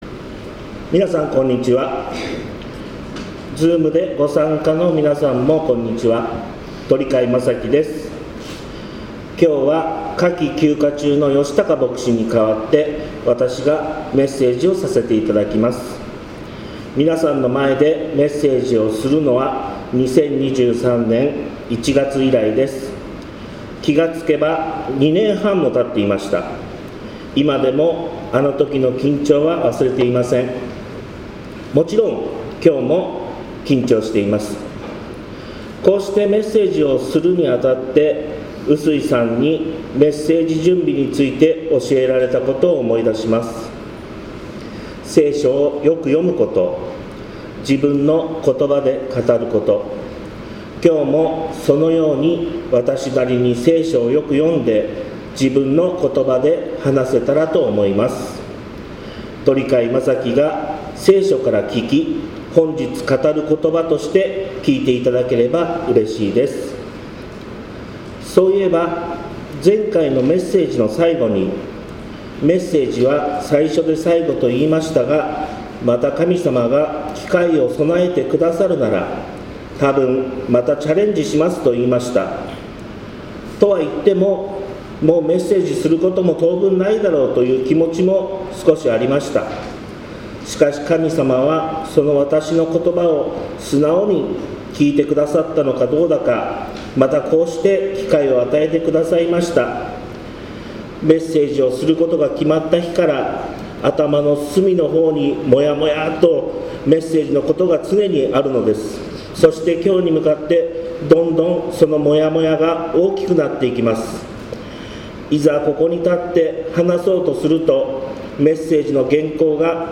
2025年8月17日礼拝「神さまが求められていること」